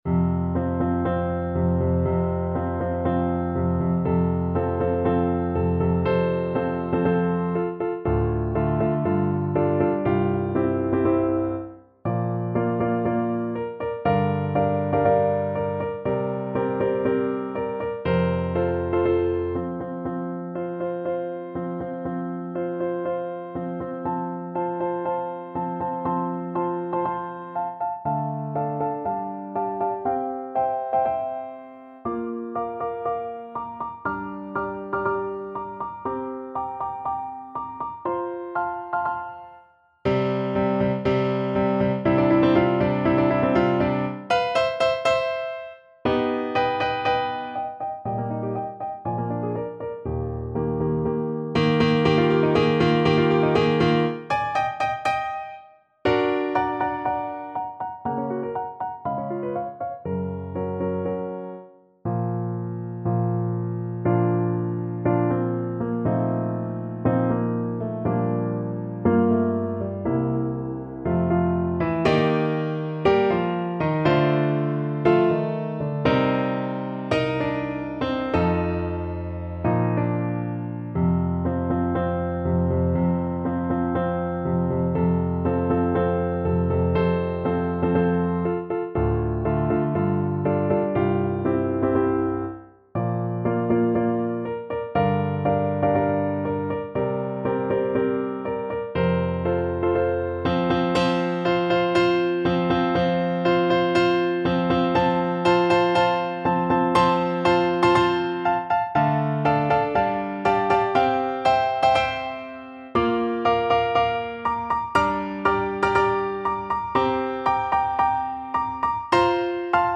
Play (or use space bar on your keyboard) Pause Music Playalong - Piano Accompaniment Playalong Band Accompaniment not yet available reset tempo print settings full screen
F major (Sounding Pitch) G major (Clarinet in Bb) (View more F major Music for Clarinet )
4/4 (View more 4/4 Music)
~ = 120 Tempo di Marcia un poco vivace
Classical (View more Classical Clarinet Music)